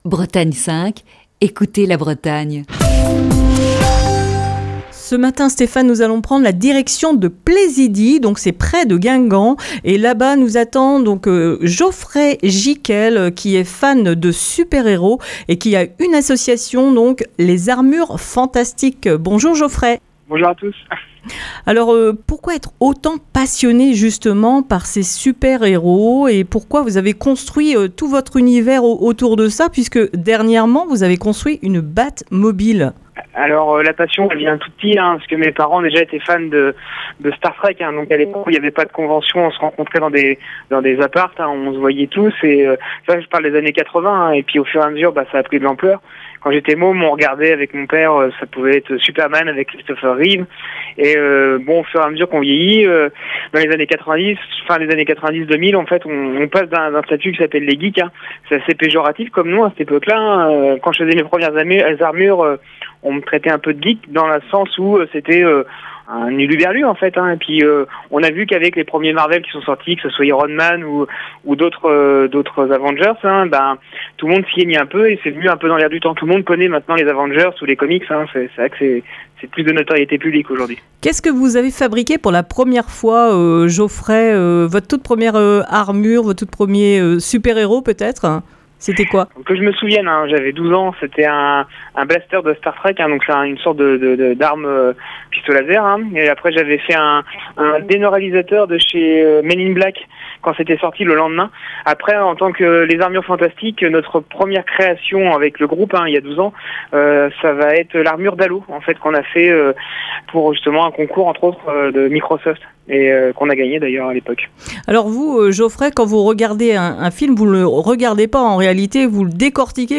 Ce Jeudi dans le Coup de fil du matin